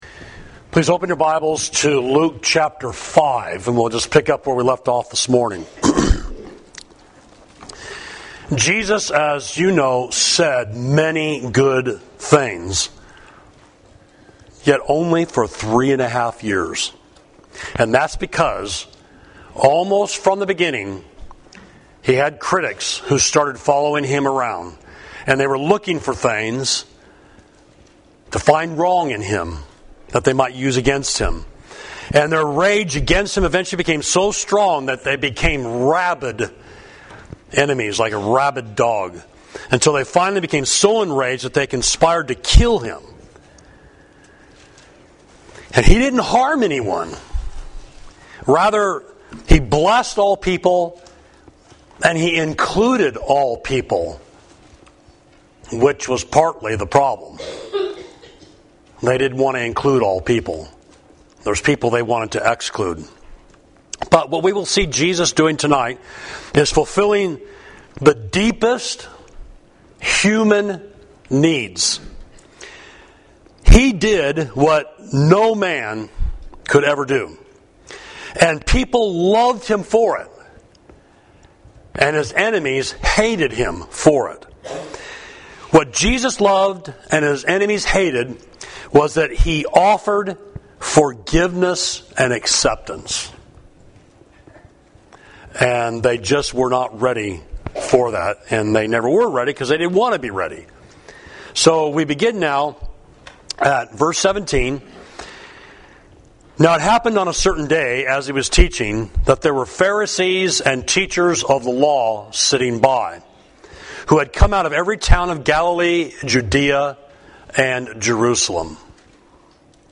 Sermon: The People of Jesus, Luke 5.27–39